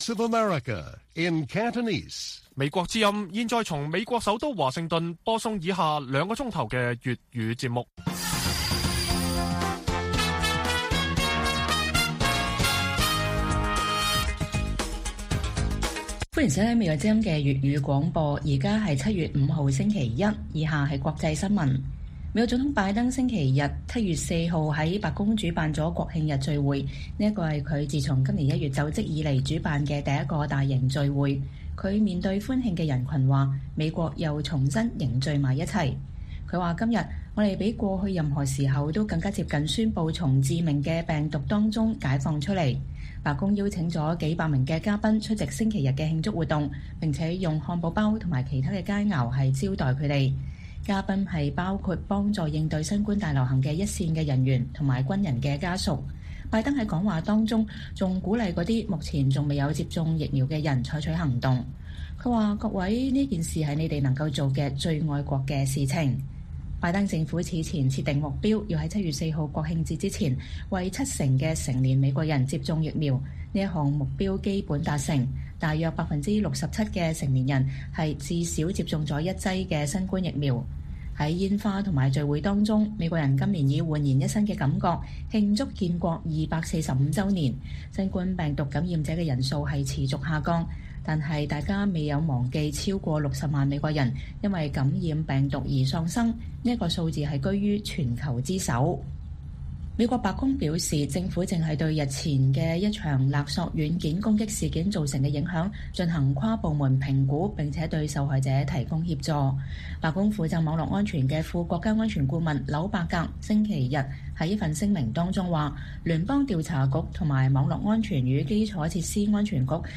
粵語新聞 晚上9-10點：拜登：“接種疫苗是最愛國的行動”